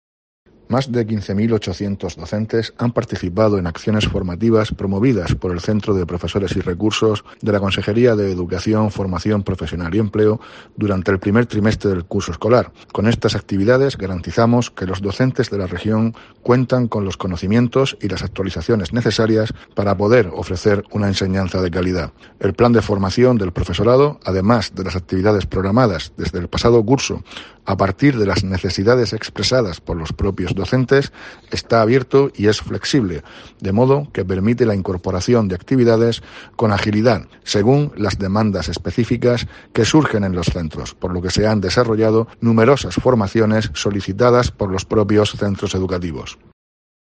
Víctor Marín, consejero de Educación, Formación Profesional y Empleo